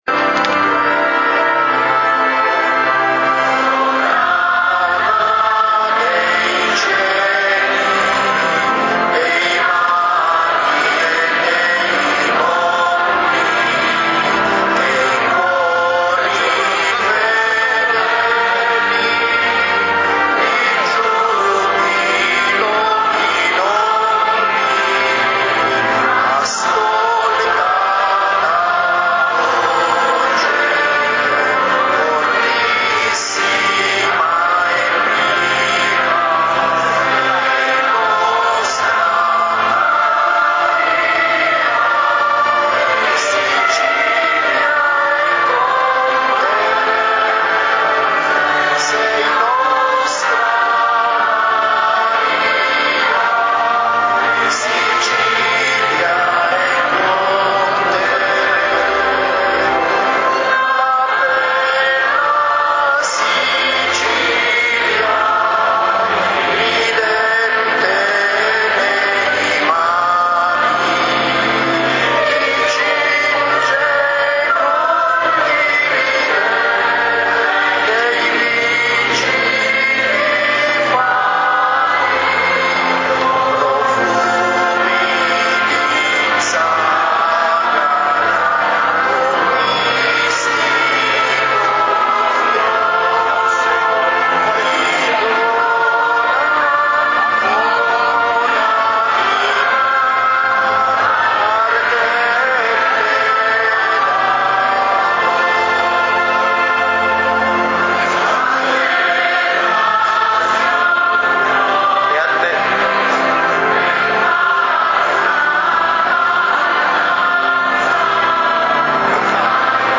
Gallery >> Audio >> Audio2013 >> Ingresso Arcivescovo Pennisi >> mp3-Sovrana dei cieli